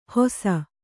♪ hosa